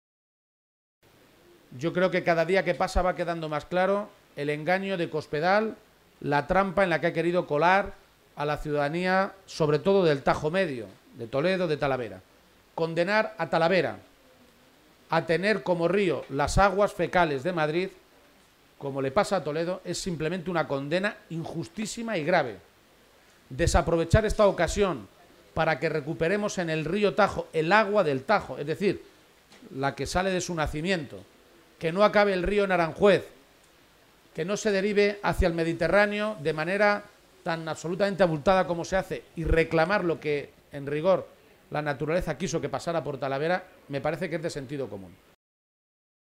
El secretario general del PSOE de Castilla-La Mancha, Emiliano García-Page, ha participado hoy en la tradicional Feria de San Isidro de Talavera de la Reina, y ha aprovechado su visita a la Ciudad de la Cerámica para compartir un encuentro con los medios de comunicación en el que ha repasado las cuestiones de actualidad regional.
Cortes de audio de la rueda de prensa